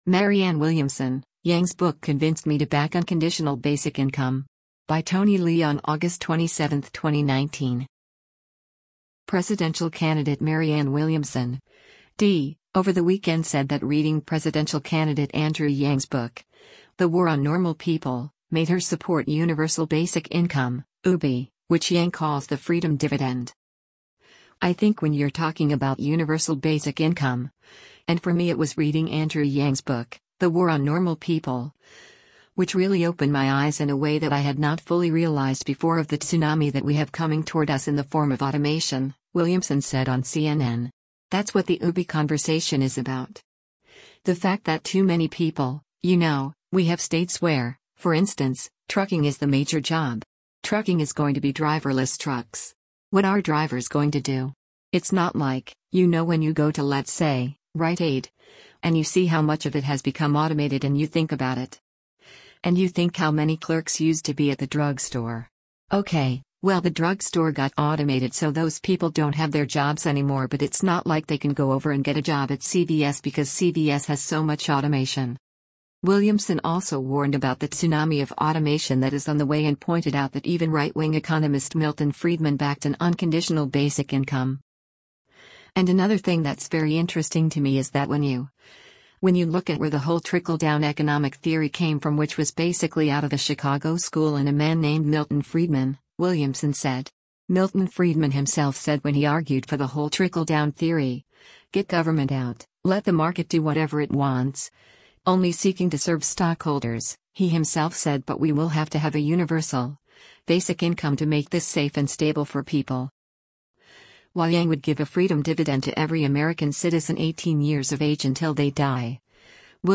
“I think when you’re talking about universal basic income, and for me it was reading Andrew Yang’s book, The War on Normal People, which really opened my eyes in a way that I had not fully realized before of the tsunami that we have coming toward us in the form of automation,” Williamson said on CNN.